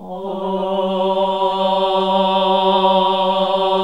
AAH G1 -L.wav